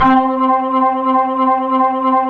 B3 TONE C4.wav